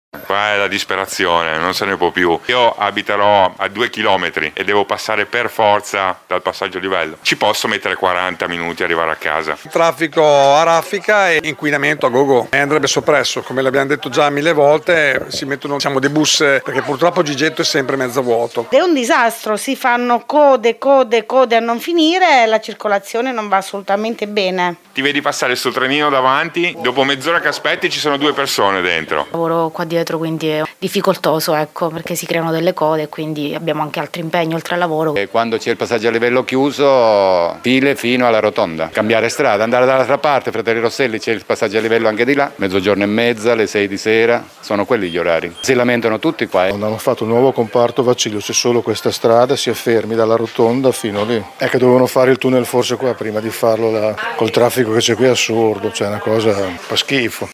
Esasperati commercianti della zona e residenti, qui sotto le interviste
VOX-MORANE-GIGETTO.mp3